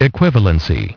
Transcription and pronunciation of the word "equivalency" in British and American variants.